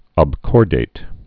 (ŏb-kôrdāt)